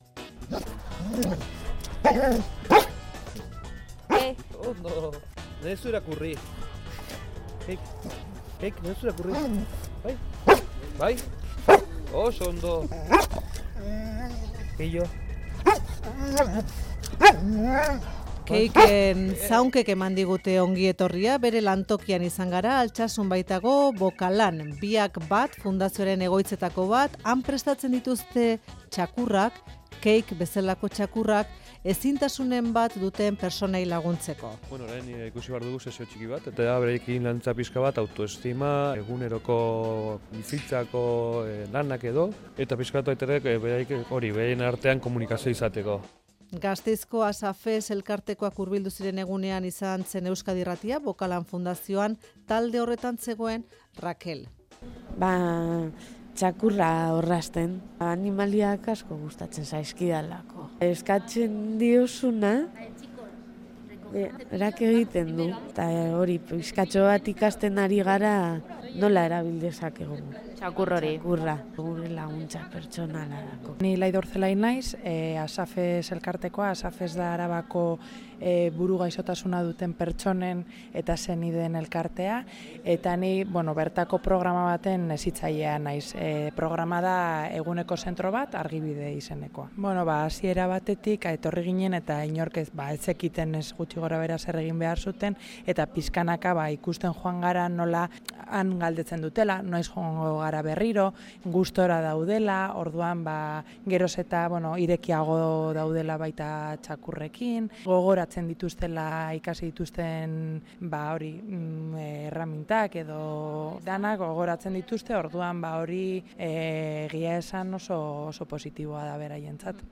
Altsasun, terapietarako txakurrak hezitzen dituen BOCALAN BIAK BAT elkartea bisitatu dugu.